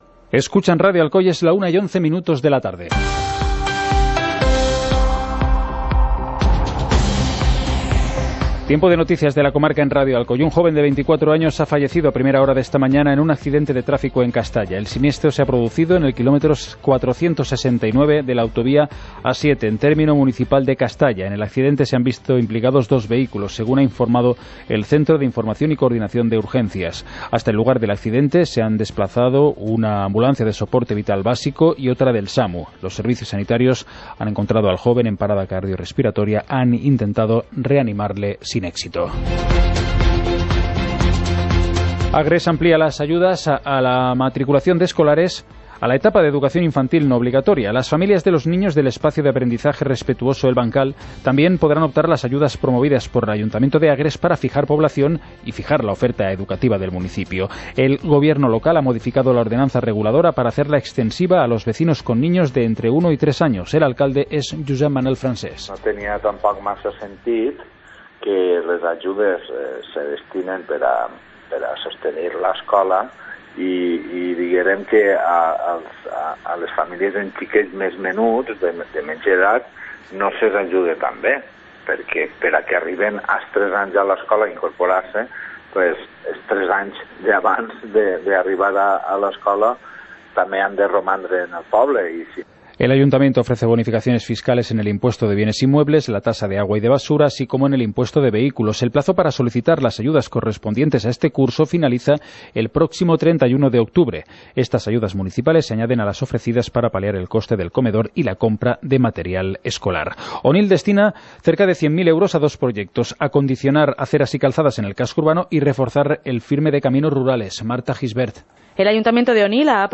Informativo comarcal - miércoles, 10 de octubre de 2018